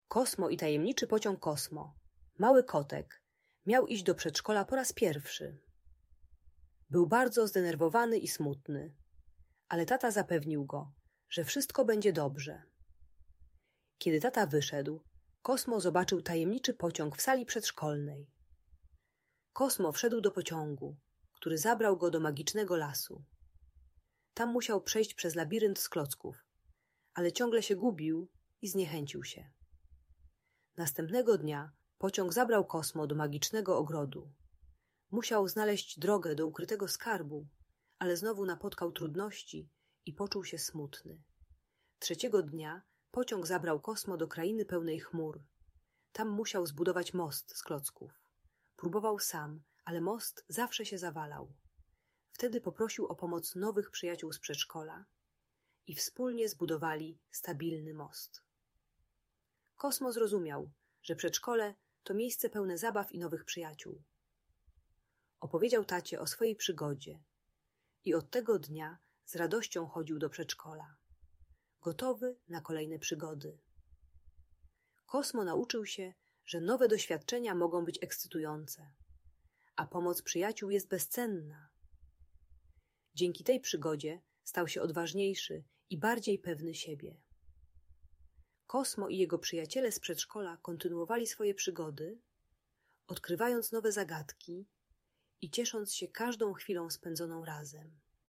Kosmo i Tajemniczy Pociąg - Audiobajka dla dzieci